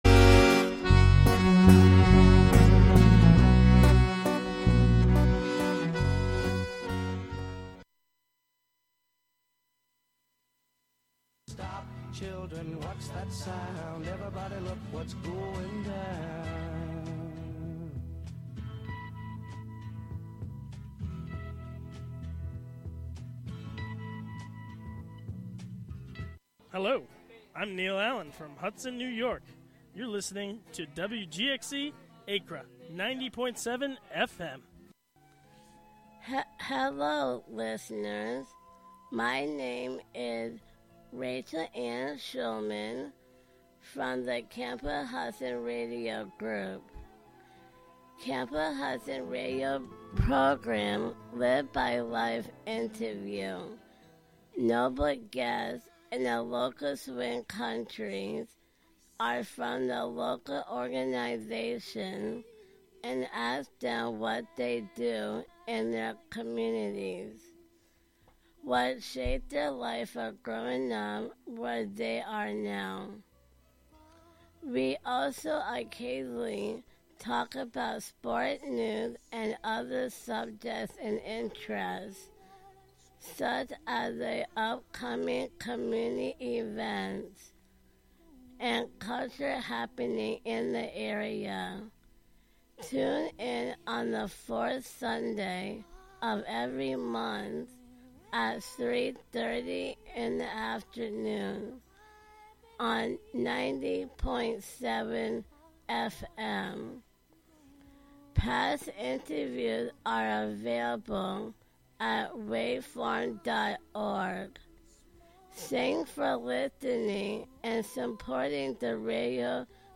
American Tarragon brings you the blissed out sounds of folk, ambient, rock-n-roll, psychedelia, electronica, new age, world music and beyond.